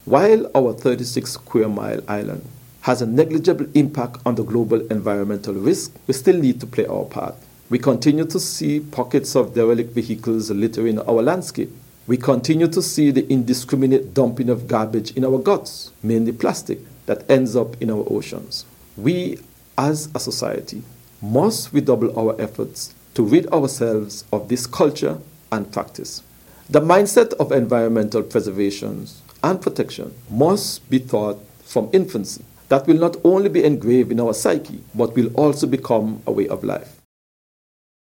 Mr. Brand urged all to help in keeping the environment clean:
Nevis’ Minister of Environment, Spencer Brand.